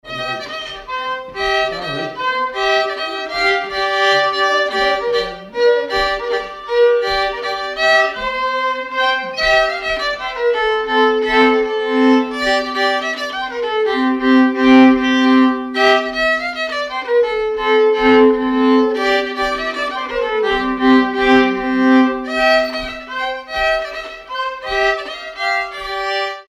Villard-sur-Doron
danse : mazurka
circonstance : bal, dancerie
Pièce musicale inédite